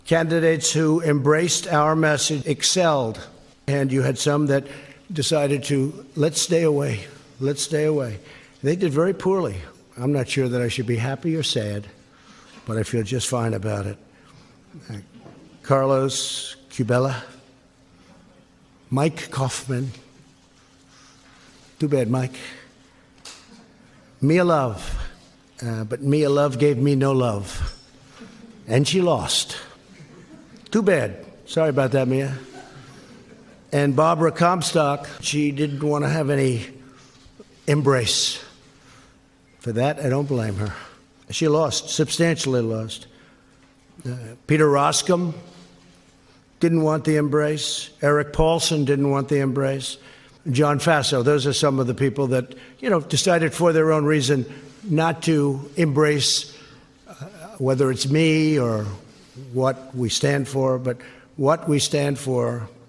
President Donald Trump said Nov. 7 that "he feels just fine about" Republican John Faso losing his Congressional election to Democrat Antonio Delgado. At a press conference at the White House, Trump blamed several Republicans, including Faso, for not having him on the campaign trail with them.